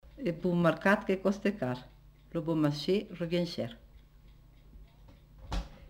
Lieu : Cathervielle
Genre : forme brève
Effectif : 1
Type de voix : voix de femme
Production du son : récité
Classification : proverbe-dicton